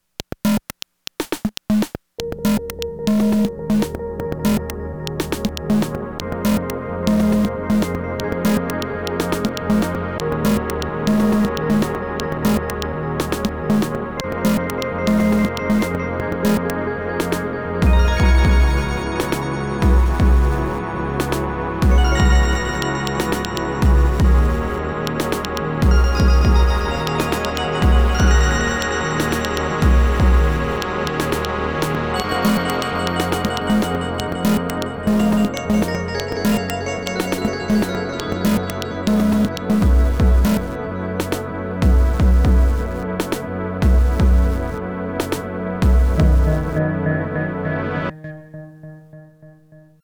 Everything other than drums is Prophet X.